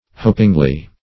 hopingly - definition of hopingly - synonyms, pronunciation, spelling from Free Dictionary Search Result for " hopingly" : The Collaborative International Dictionary of English v.0.48: Hopingly \Hop"ing*ly\, adv. In a hopeful manner.
hopingly.mp3